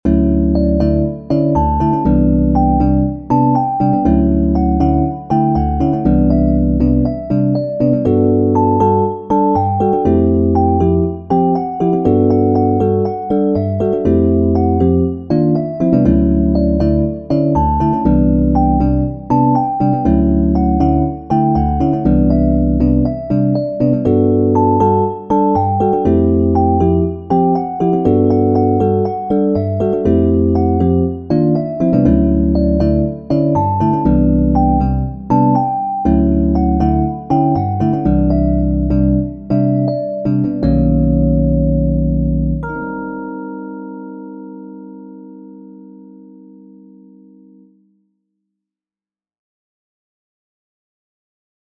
SVG 钢琴谱 + MIDI 合成试听音频。一闪一闪亮晶晶~
「一闪一闪亮晶晶」轻松氛围改编在钢琴上
最近感觉比较浮躁，于是用比较轻松的氛围感改编了这首曲子。
这个是 MIDI 合成的试听音频： 点击此处在新窗口播放音频